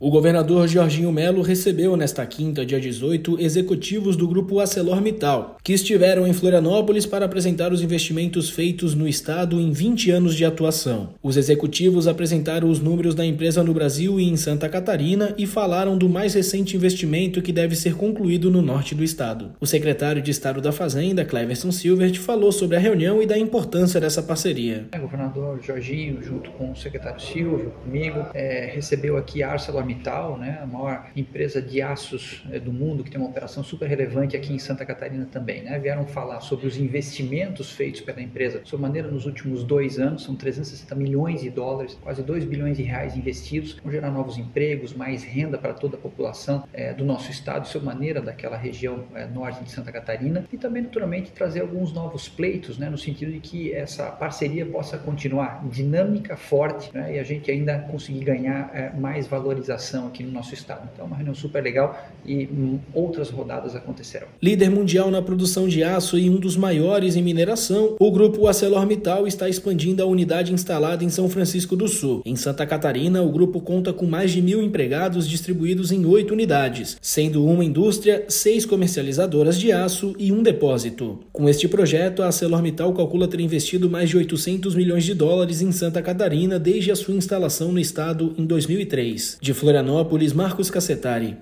O secretário de Estado da Fazenda, Cleverson Siewert, falou sobre a reunião e da importância dessa parceria: